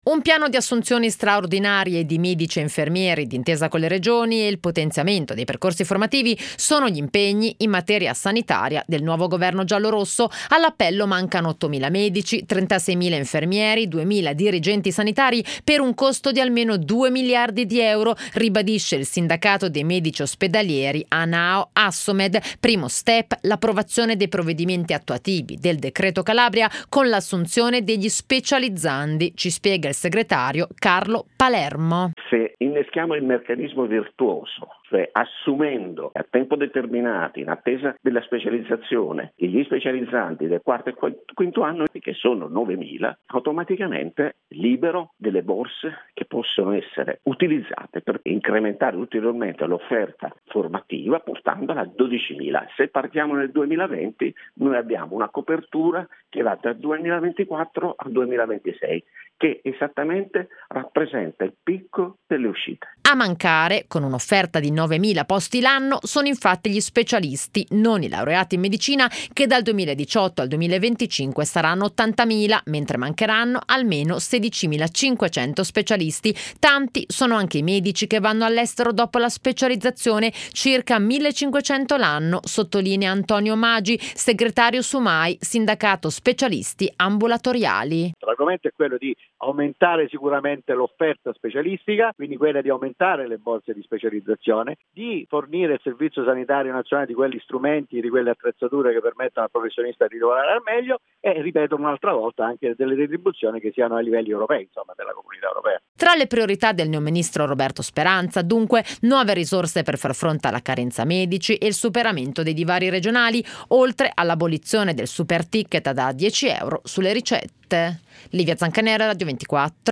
intervenendo su Radio 24, la radio del Sole 24ore, nel corso della trasmissione radiofonica “Effetto giorno”.